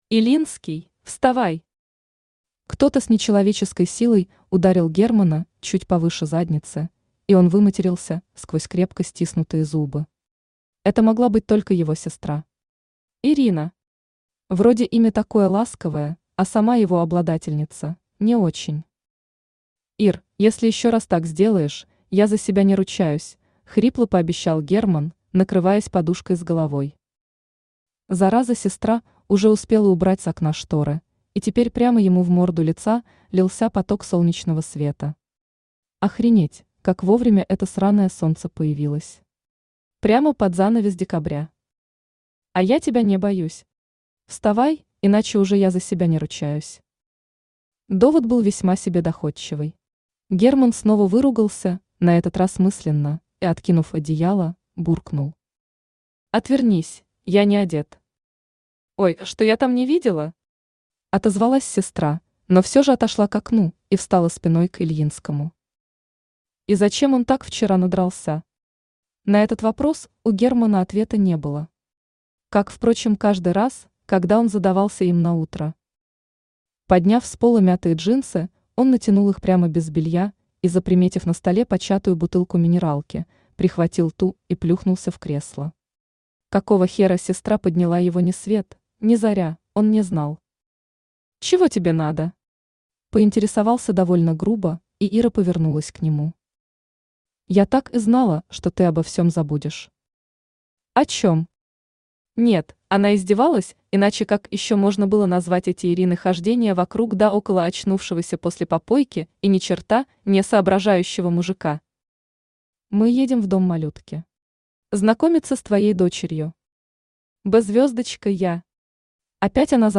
Aудиокнига В постели с монстром Автор Тати Блэк Читает аудиокнигу Авточтец ЛитРес.